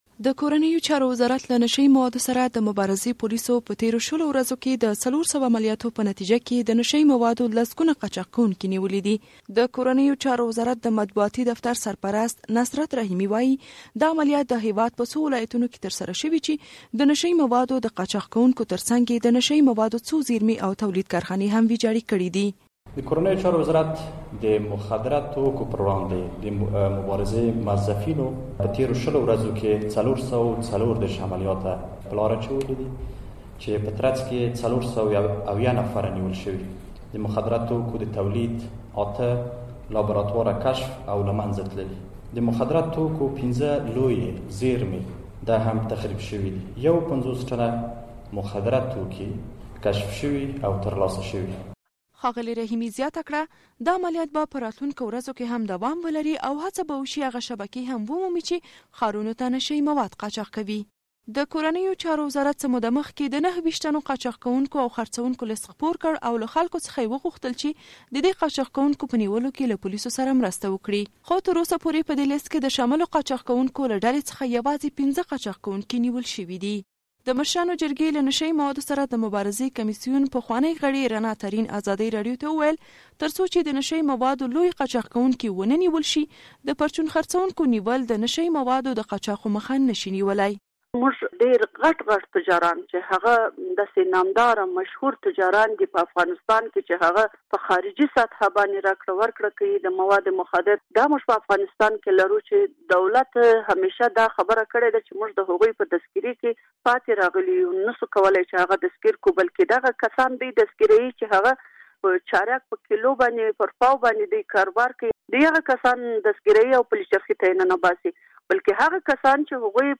فیچر